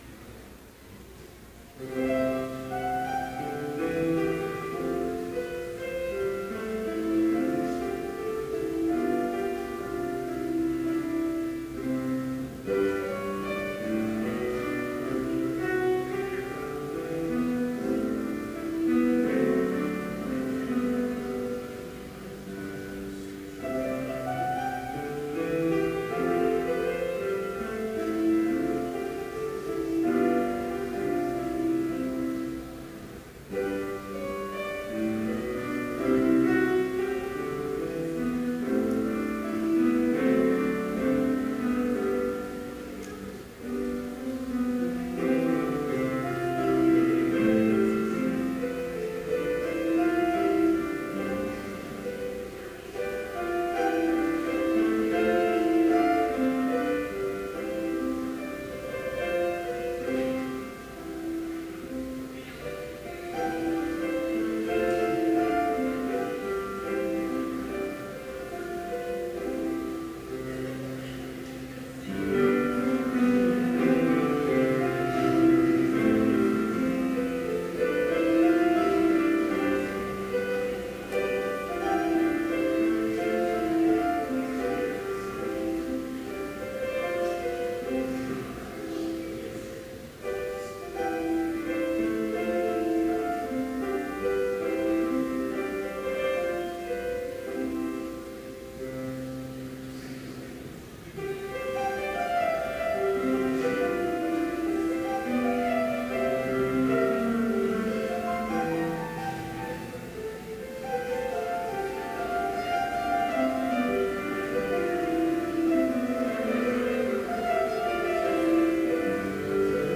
Complete service audio for Chapel - January 23, 2015